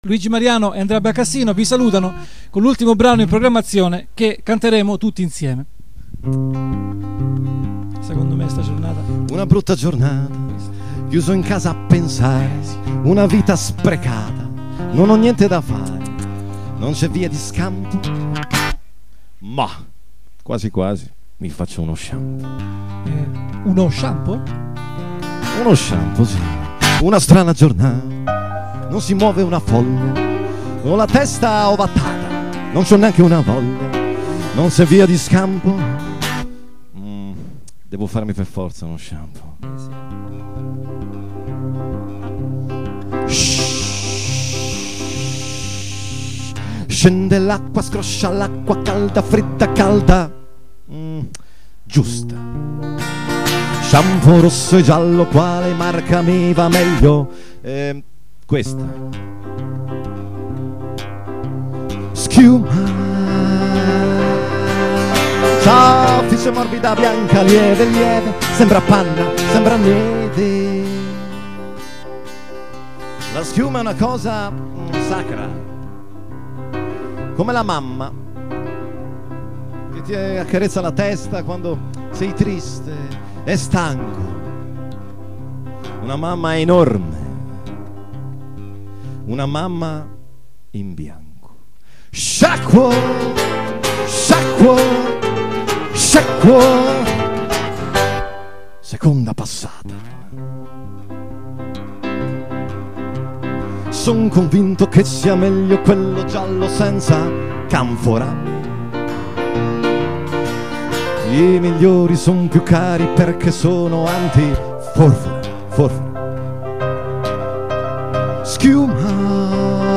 spettacolo tributo al grande
voce, chitarra
voce, piano, monologhi
audio live 4 settembre 2007